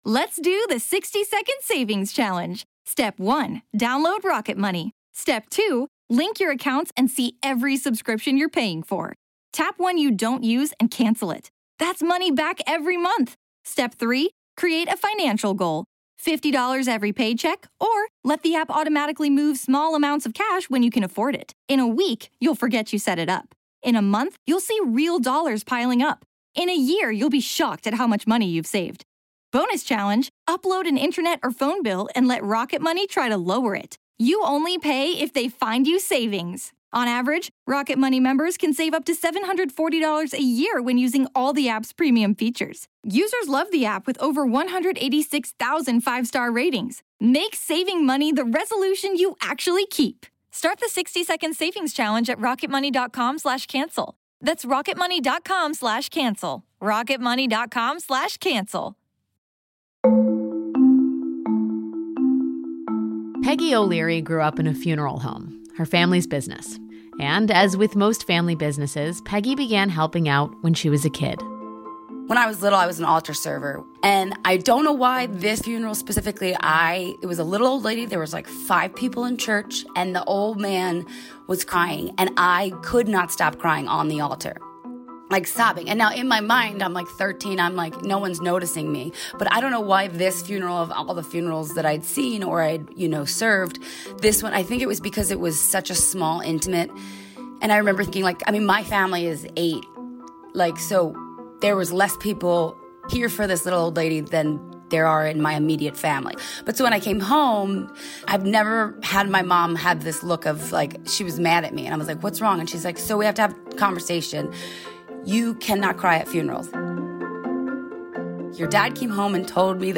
This week, Alex talks to a funeral director and a Rabbi about how Covid-19 is affecting our regular rituals -- which also happen to be their jobs.